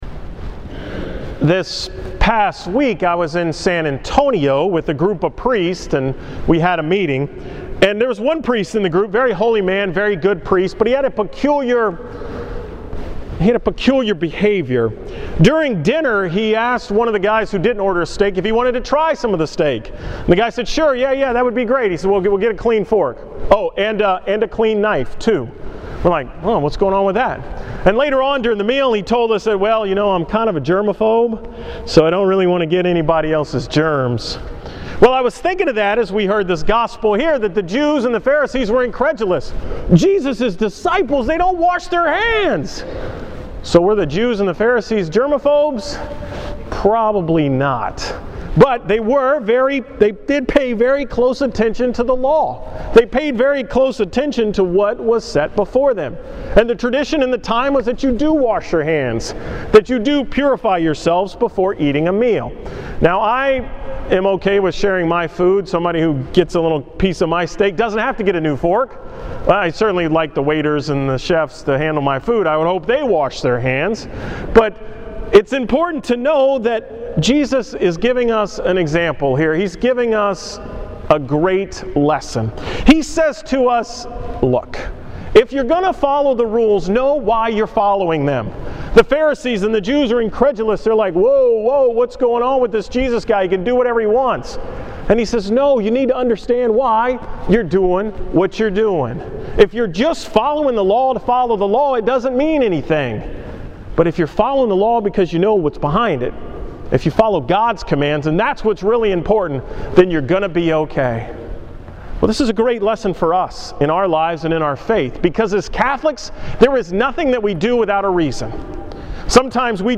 Homily from September 2nd
Here is the homily from the 22nd Sunday in Ordinary Time